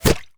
bullet_impact_mud_08.wav